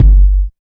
44 KICK 2.wav